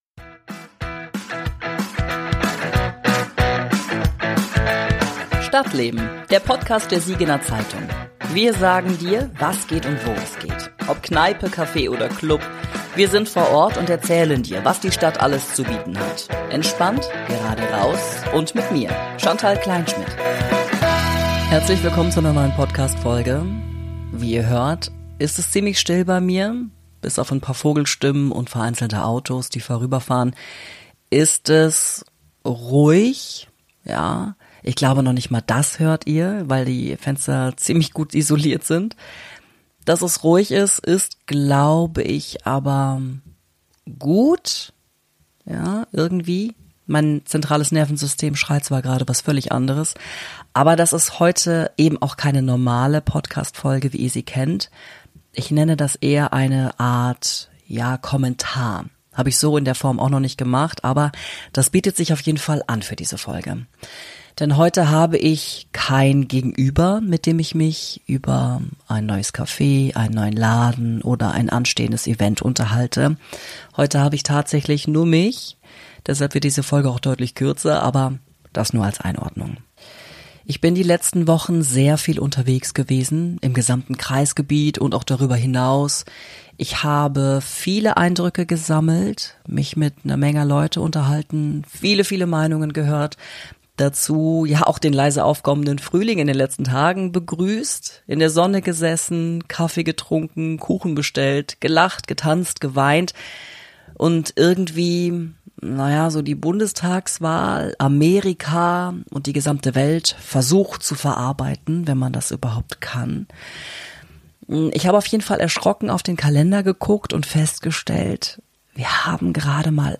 Stadtleben – Kommentar